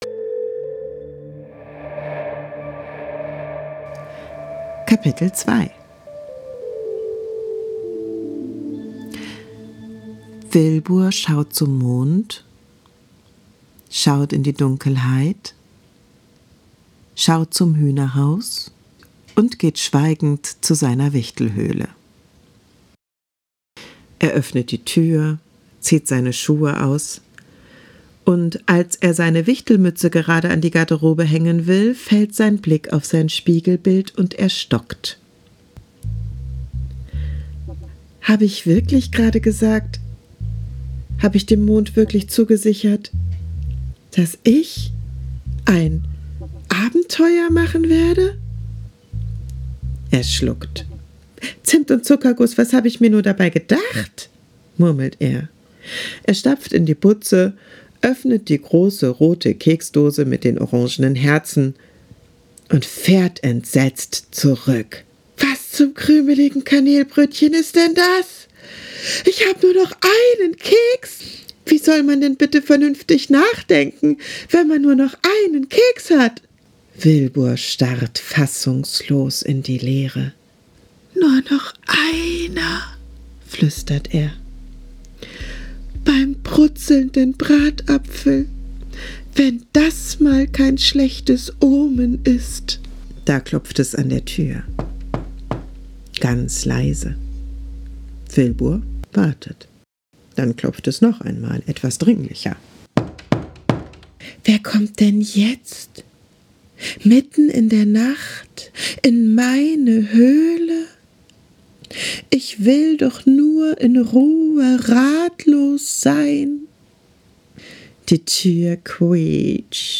Eine ruhige Weihnachtsgeschichte für Kinder ab 4 Jahren.